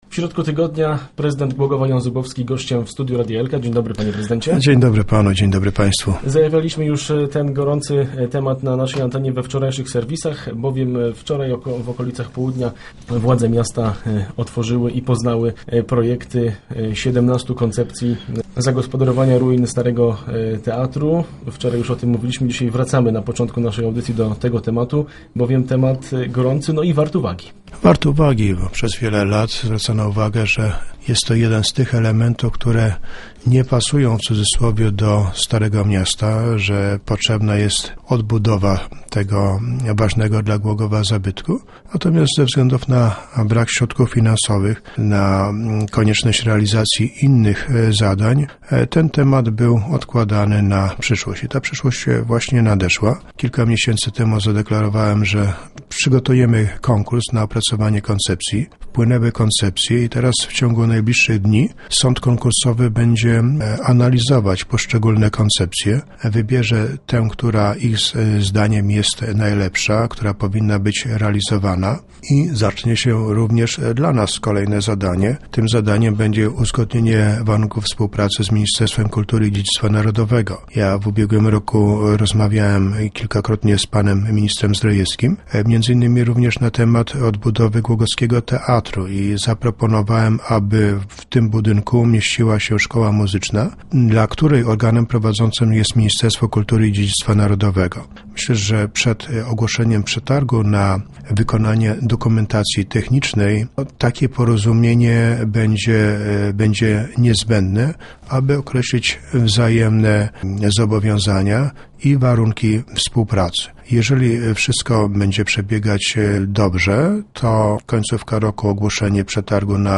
O odbudowie teatru, kontroli w urzędzie miasta, a także działkach budowlanych na sprzedaż rozmawialiśmy z prezydentem Głogowa Janem Zubowskim podczas cotygodniowej wizyty w radiowym studiu.